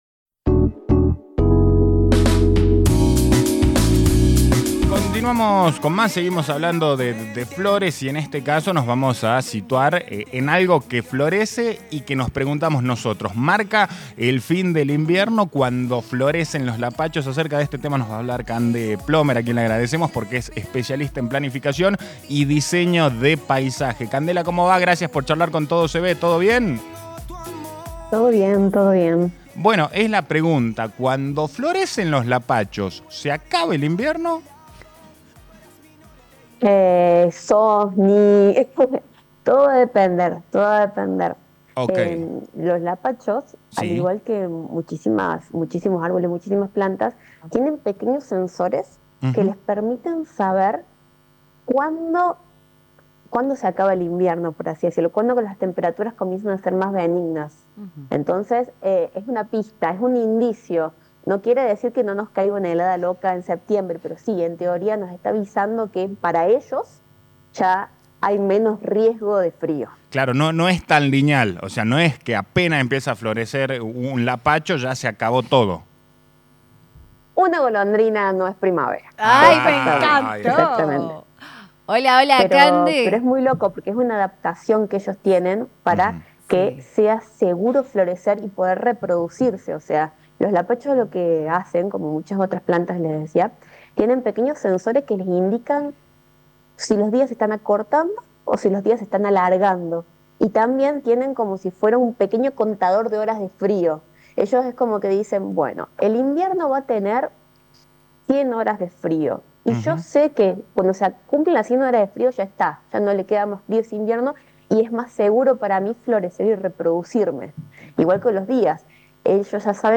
Una charla súper interesante con la Ingeniera agrónoma que vale la pena ser escuchada, no solo para los antes de las plantas, sino para todo aquel que disfruta de ver “un Lapacho en Flor